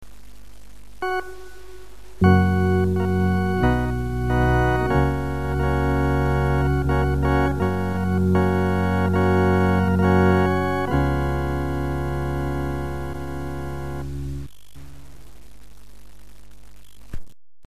Masonic Music for Lodge and Chapter
Organ.
It is the chant when sealing the obligation on the VSL in the 2nd degree "Keep Thy Tongue - - - -".